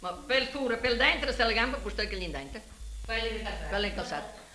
TRA FERRARA E RAVENNA: ARGENTA, FILO, LONGASTRINO, LAVEZZOLA
indovinello.mp3